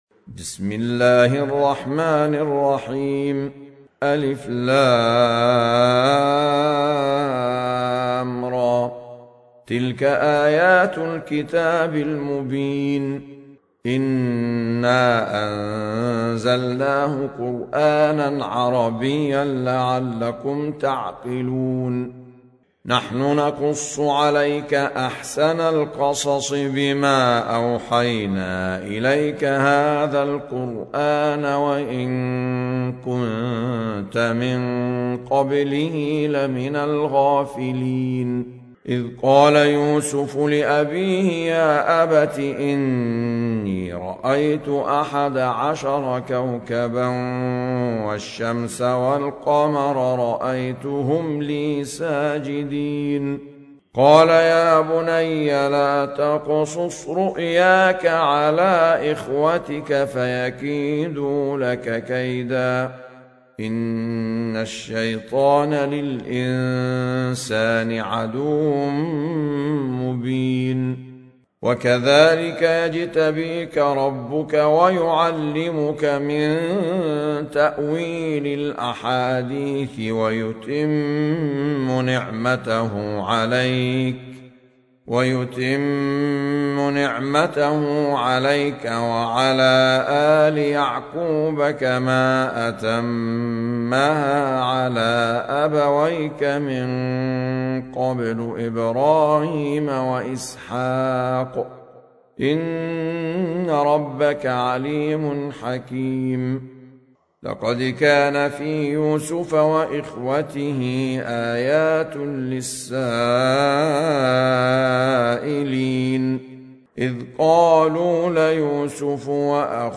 سورة يوسف | القارئ أحمد عيسي المعصراوي